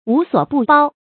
無所不包 注音： ㄨˊ ㄙㄨㄛˇ ㄅㄨˋ ㄅㄠ 讀音讀法： 意思解釋： 包：包容。